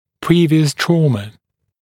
[‘priːvɪəs ‘trɔːmə][‘при:виэс ‘тро:мэ]ранее полученная травма (-ы)